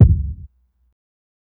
KICK_BANG_BOOGIE.wav